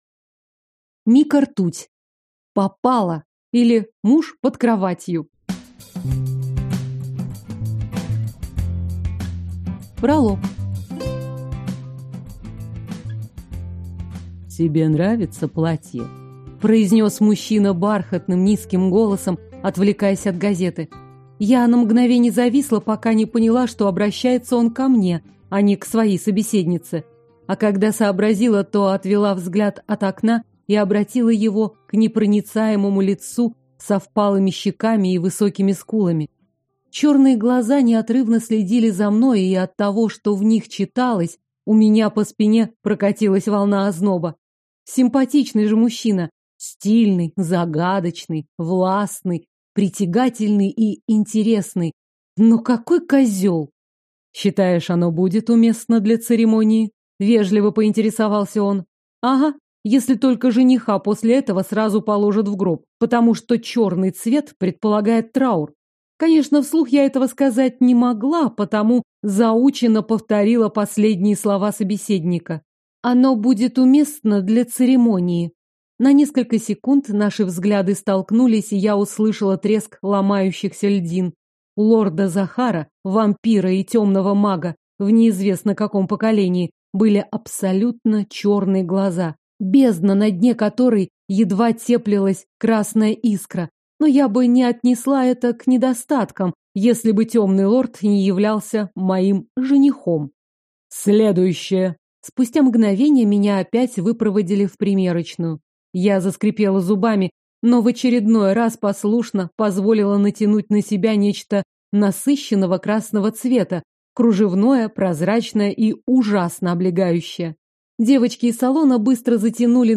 Aудиокнига Попала, или Муж под кроватью Автор Мика Ртуть Читает аудиокнигу